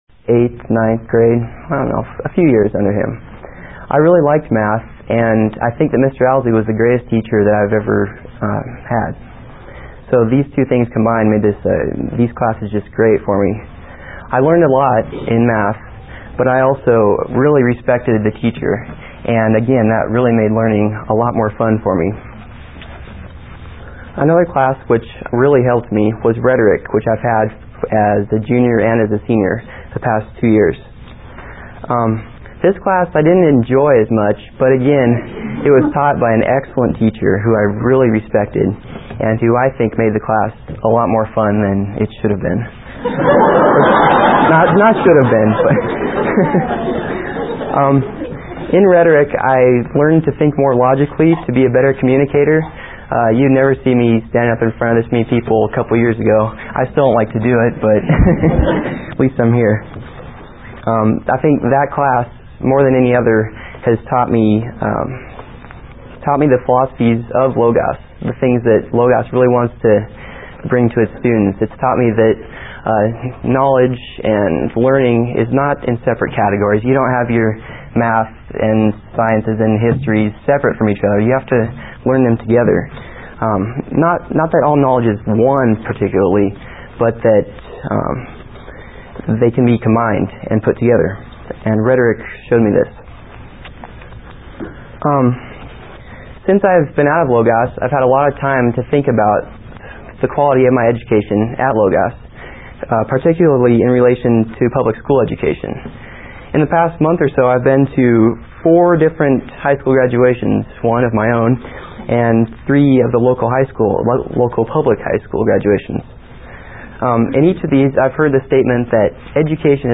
1995 Workshop Talk | 0:52:09 | All Grade Levels